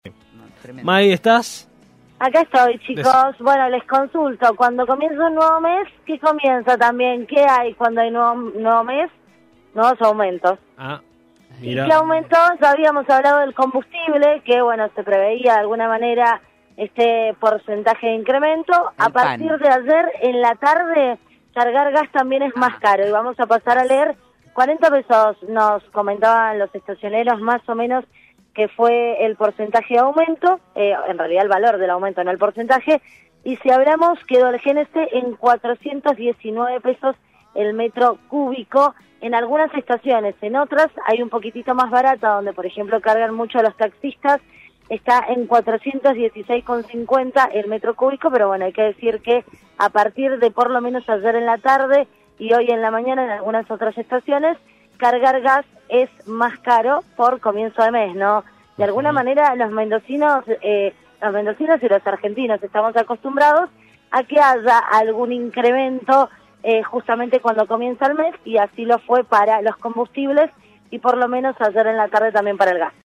Móvil de LVDiez- Aumentó el GNC